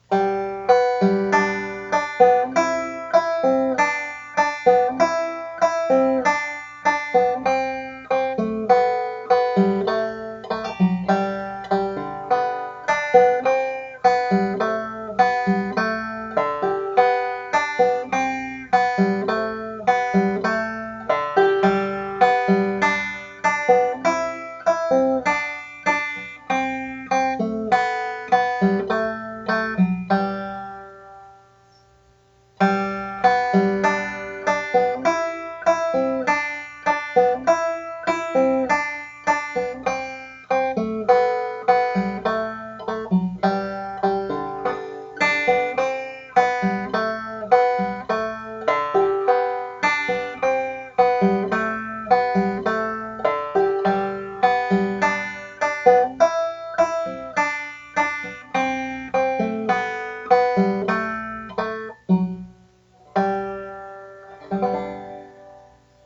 banjo
Music: Czech traditional